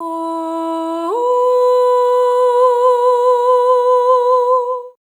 SOP5TH E4B-L.wav